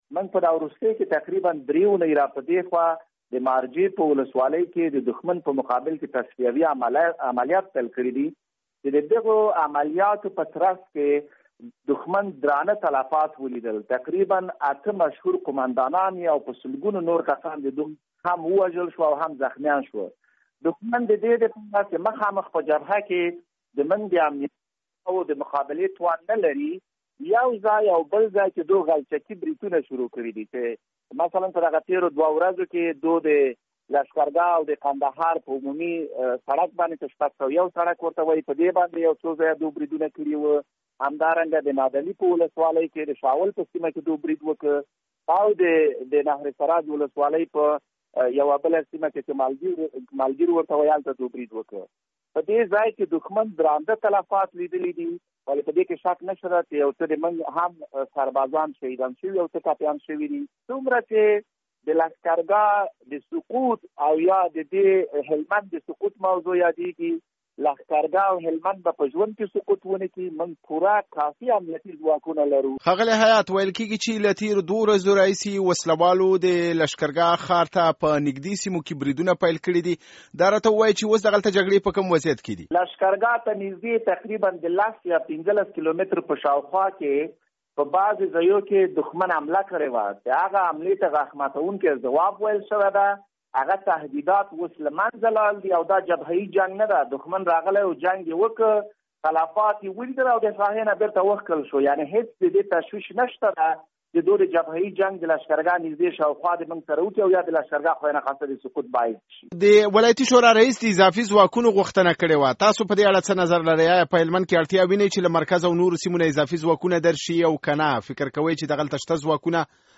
ښاغلي حیات پرون دوشنبې ماښام ازادي راډیو ته وویل چې هلمند ته ځانګړي ځواکونه وررسیدلي او دا مهال په هغو سیمو کې چې وسله وال برید کړی په تصفیوي عملیاتو بوخت دي څو دغلته ممکن پټ شوي وسله وال وباسي.